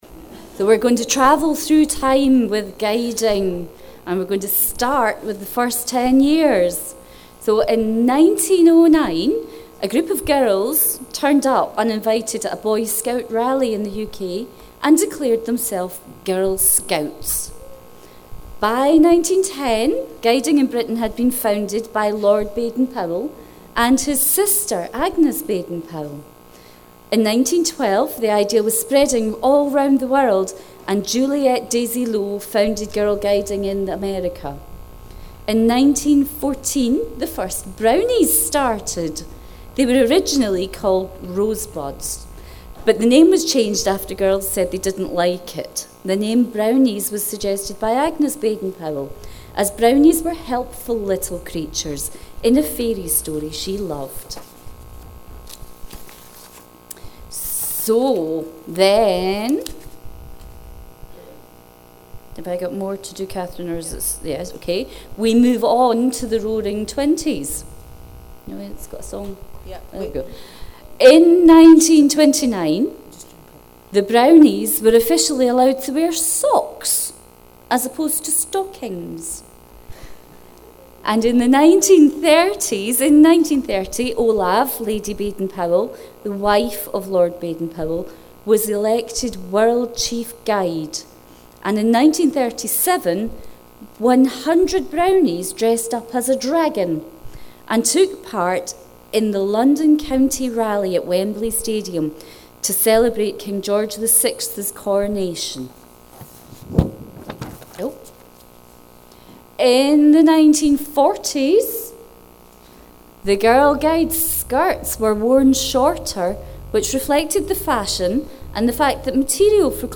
Travelling in time story
Thinking Day - 19 February 2019